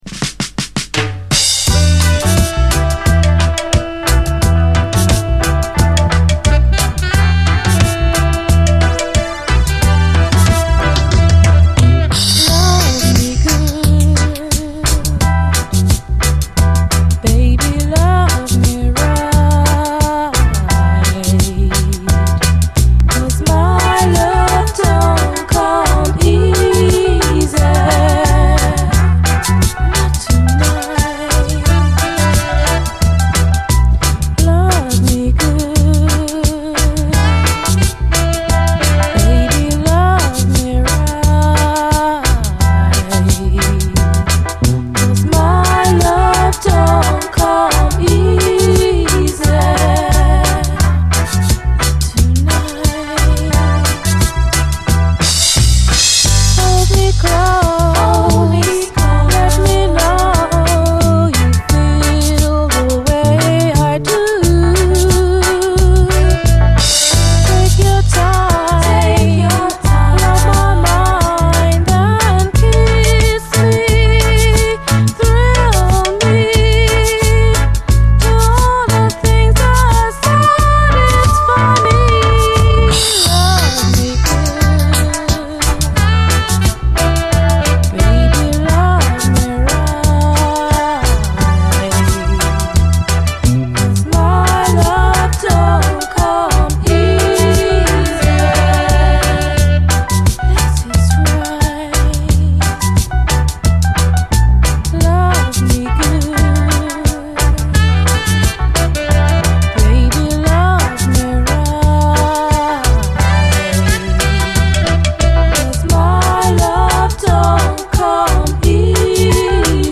後半はダブに接続。